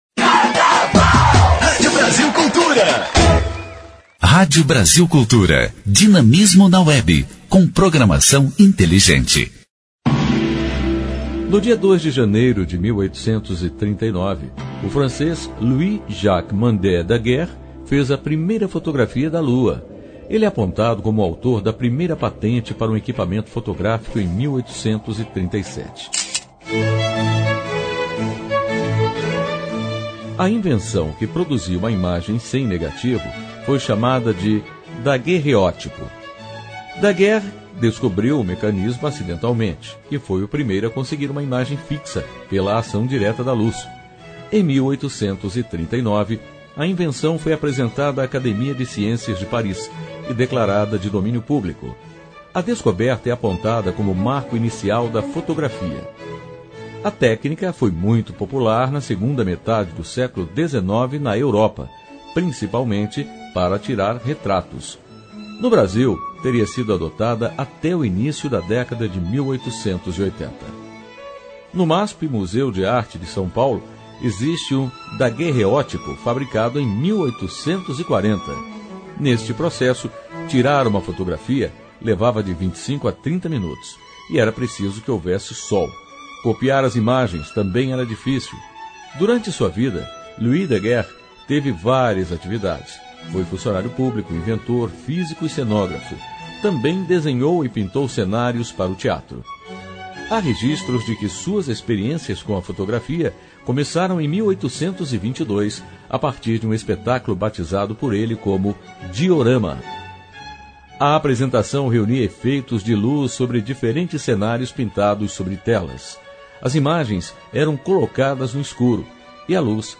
História Hoje: Programete sobre fatos históricos relacionados às datas do calendário. Vai ao ar pela Rádio Brasil Cultura de segunda a sexta-feira.